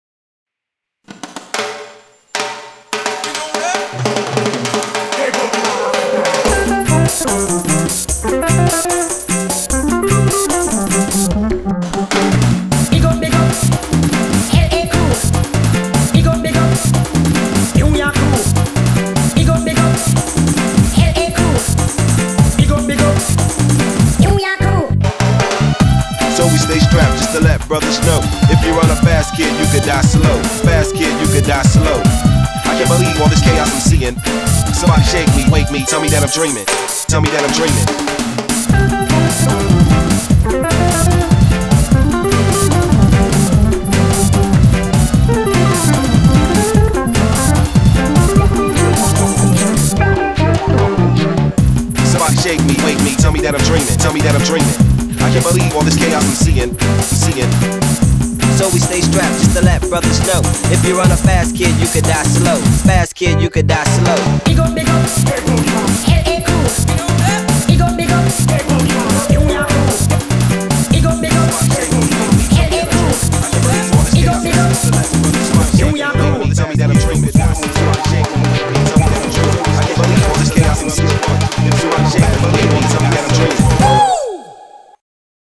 Audio QualityPerfect (High Quality)